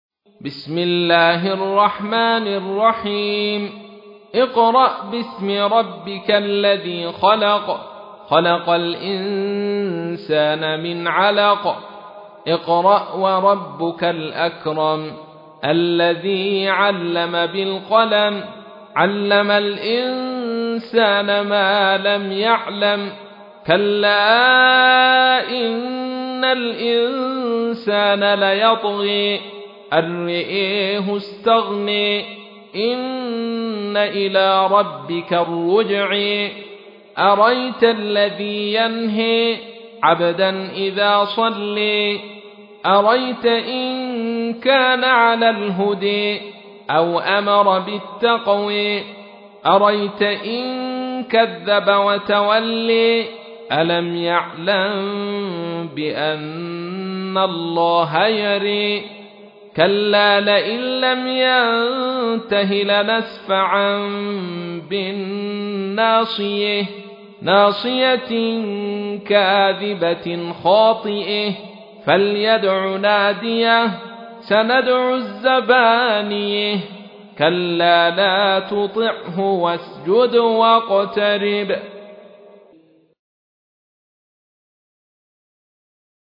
تحميل : 96. سورة العلق / القارئ عبد الرشيد صوفي / القرآن الكريم / موقع يا حسين